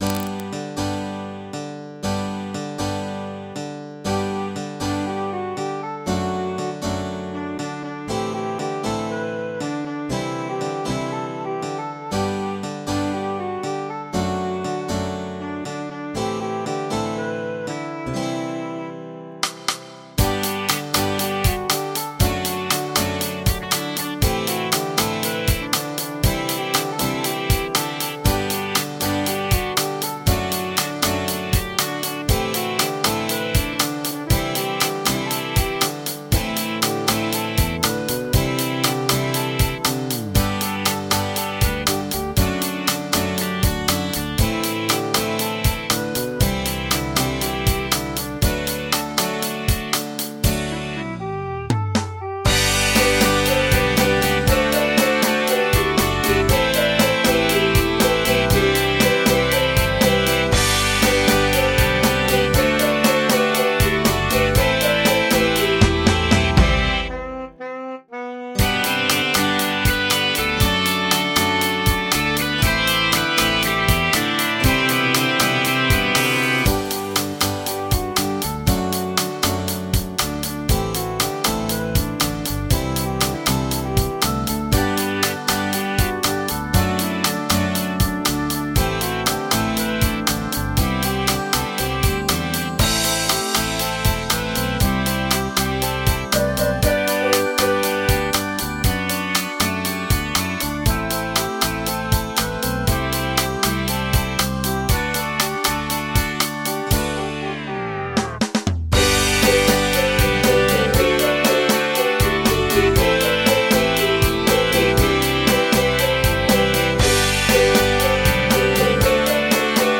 MP3 (Converted)